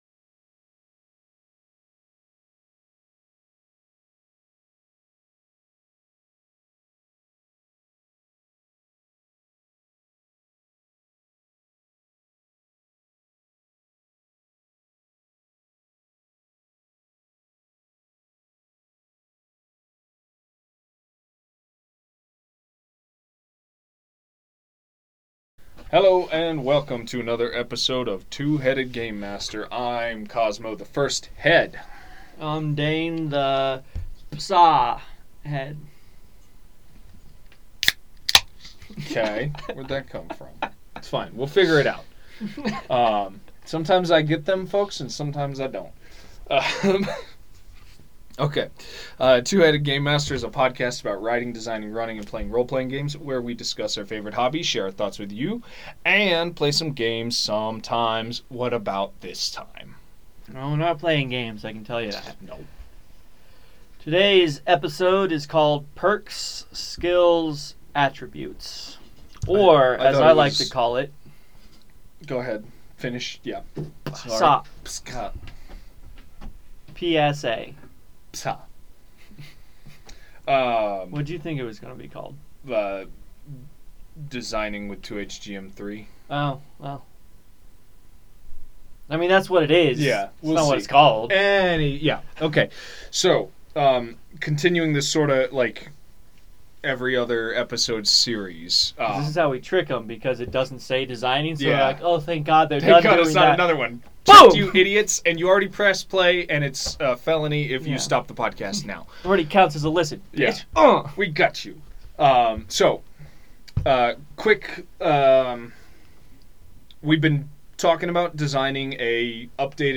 2HGM is podcast about writing, designing, running, and playing Role-Playing Games. Two best friends discuss their favorite hobby, and share their thoughts on the internet.